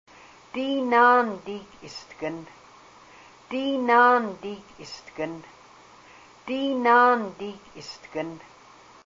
The following are a collection of phrases recorded with native speakers of Haida during a trip to Ketchikan and Hydaburg, Alaska, in March, 2003.
a native speaker of the Kasaan dialect of Alaskan Haida.